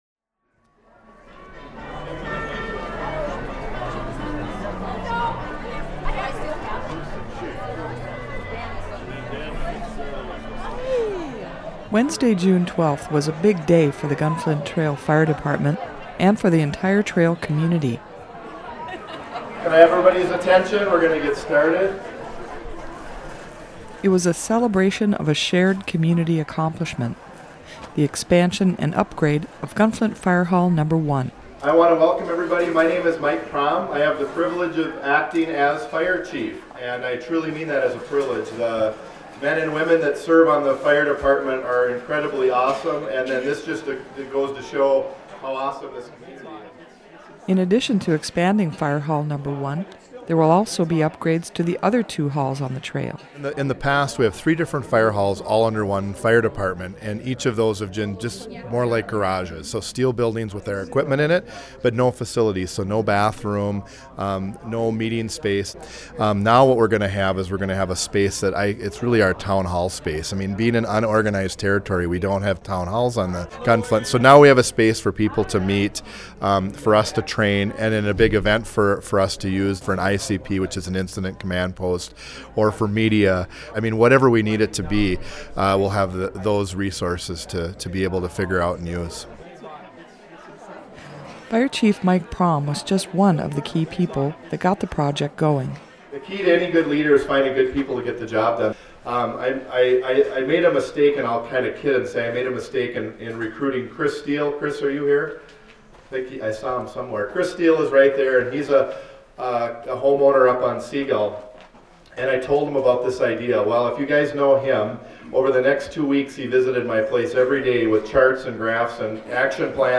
The Gunflint Trail Volunteer Fire Department held a grand opening celebration for the newly expanded and upgraded Poplar Firehall, Wednesday, June 12, 2013.